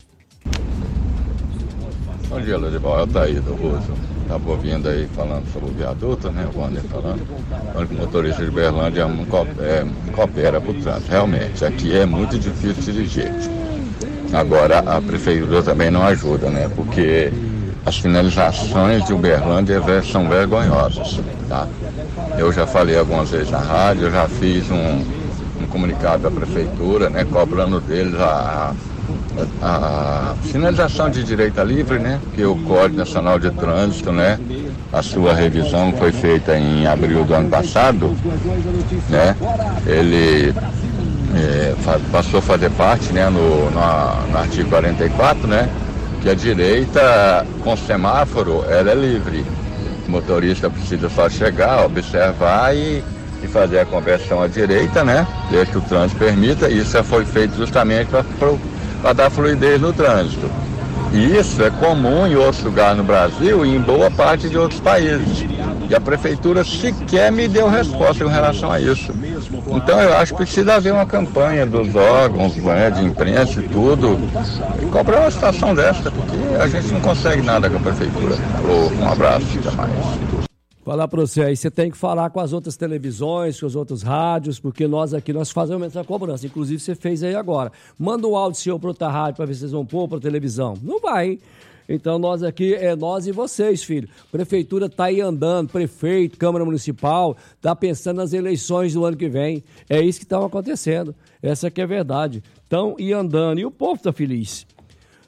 – Ouvinte reclama que as sinalizações viárias na cidade são muito ruins.